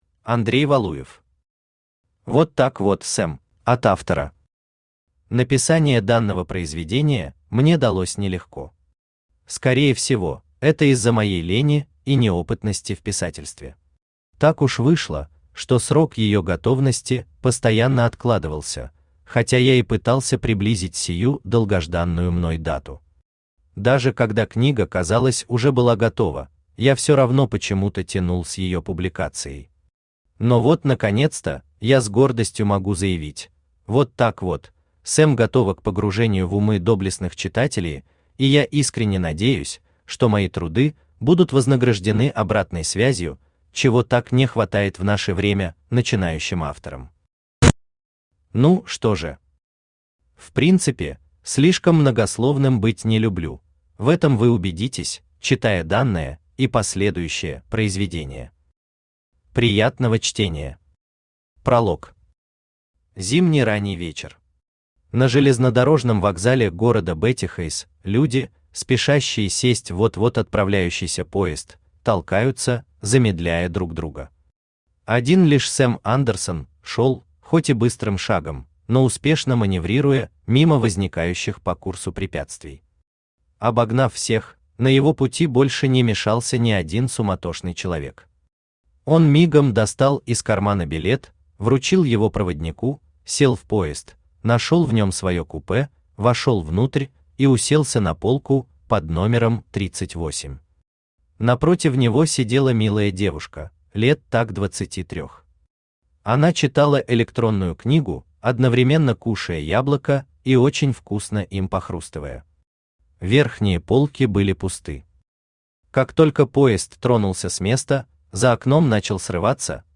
Аудиокнига Вот так вот, Сэм | Библиотека аудиокниг
Aудиокнига Вот так вот, Сэм Автор Андрей Валуев Читает аудиокнигу Авточтец ЛитРес.